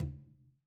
Percussion
Tumba-HitN_v3_rr2_Sum.wav